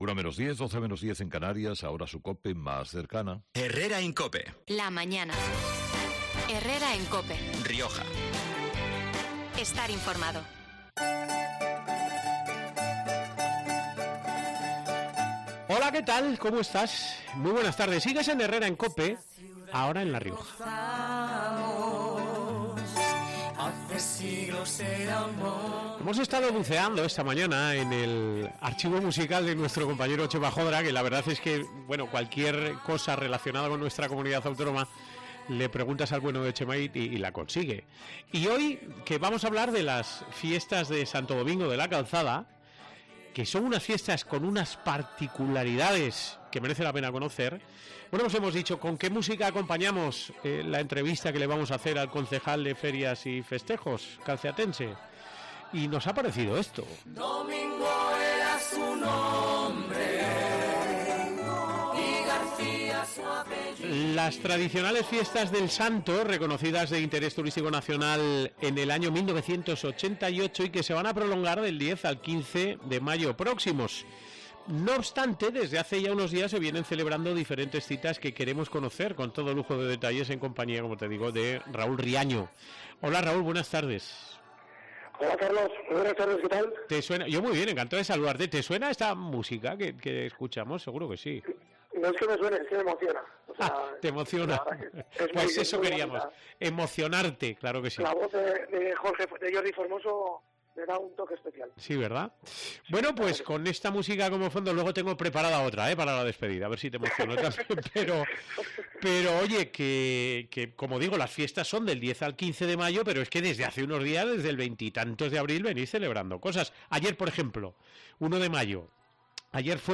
El concejal calceatense de Ferias y Festejos, Raúl Riaño, ha estado este 2 de mayo en COPE Rioja para hablar de las fiestas y de los actos previos que se viene celebrando estos días.